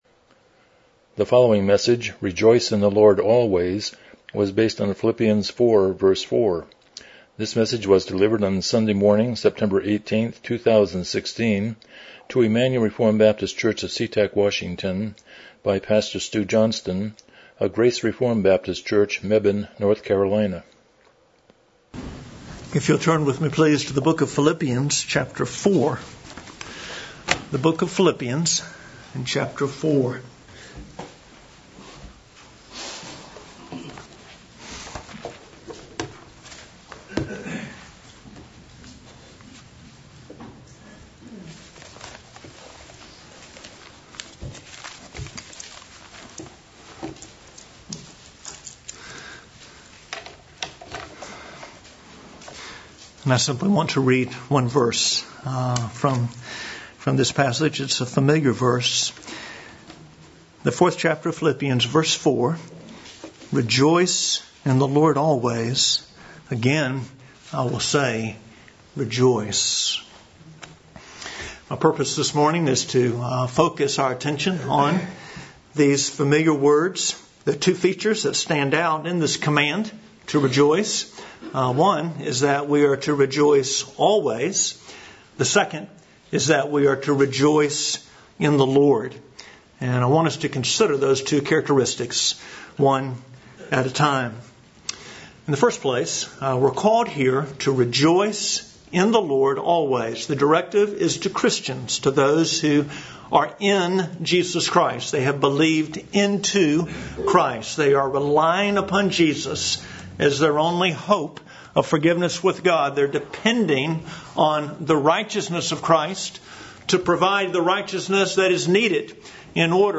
Philippians 4:4 Service Type: Morning Worship « Husbands